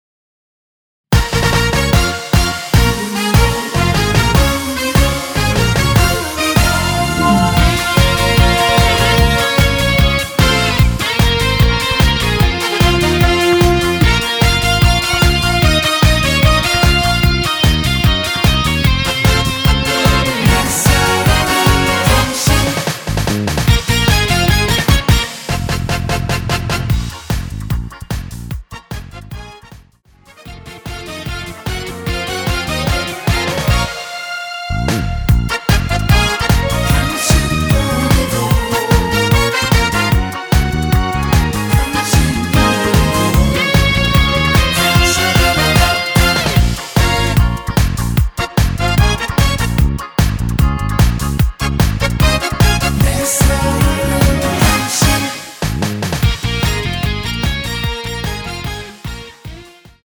원키에서(-1)내린 코러스 포함된 MR 입니다.
Bbm
앞부분30초, 뒷부분30초씩 편집해서 올려 드리고 있습니다.